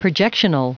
Prononciation du mot projectional en anglais (fichier audio)
Prononciation du mot : projectional